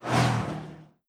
push_notification.wav